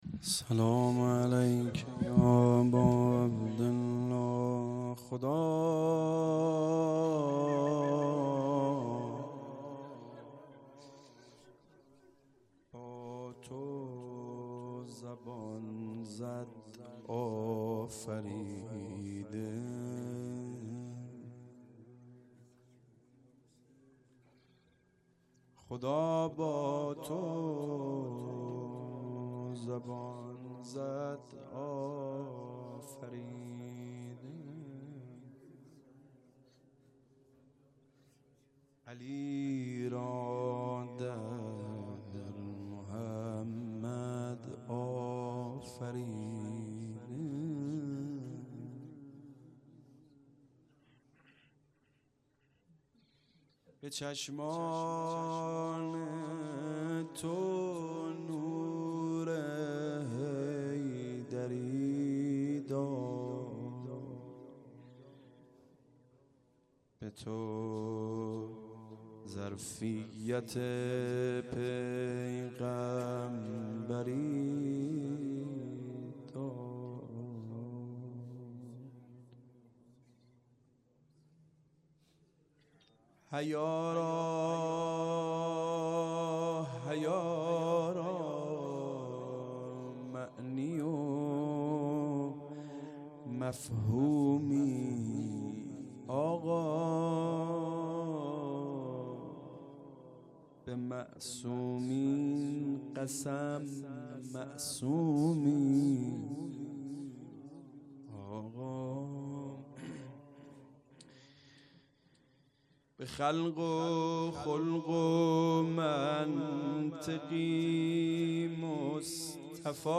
هيأت یاس علقمه سلام الله علیها
محرم 1440 _ شب هشتم